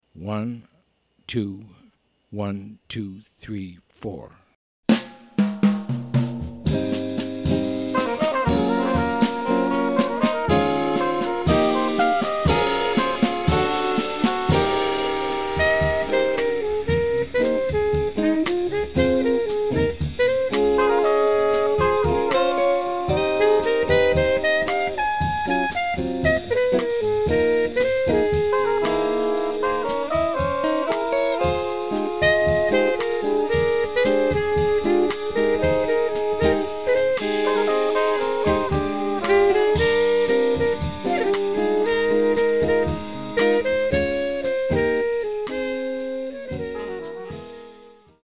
Almost a pop ballad